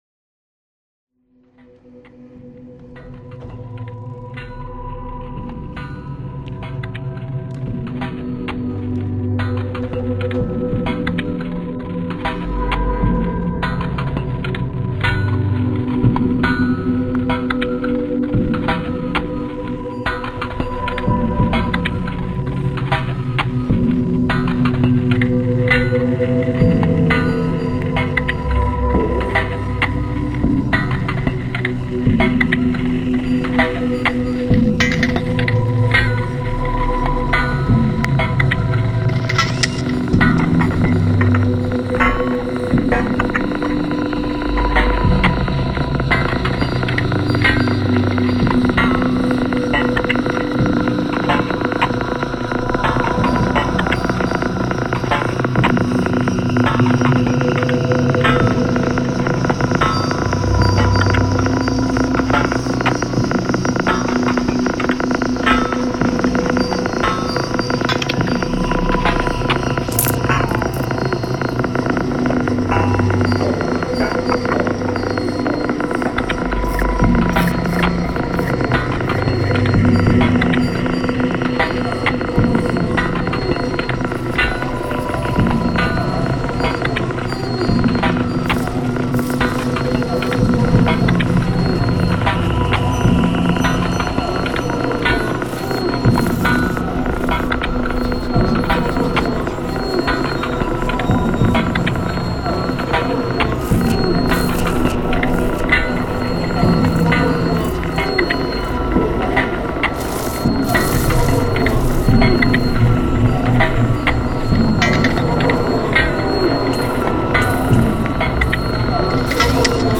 live 23.11.2002